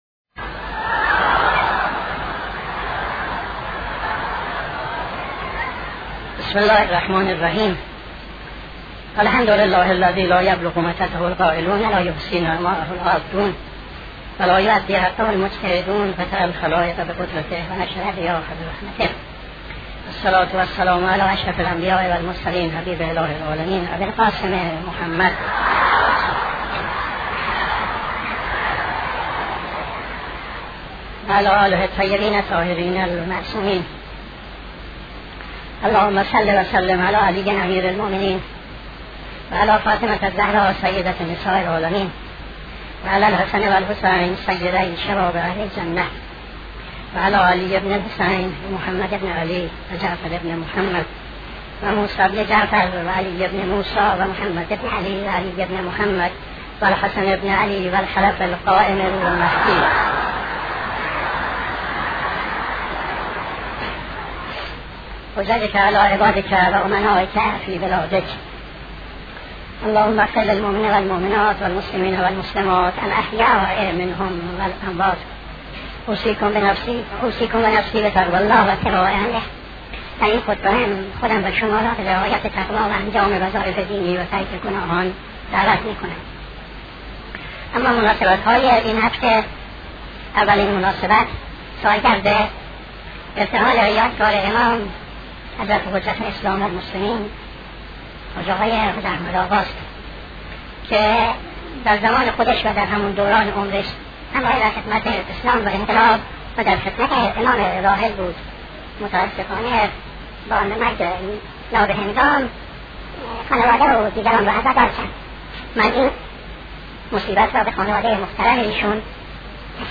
خطبه دوم نماز جمعه 21-12-76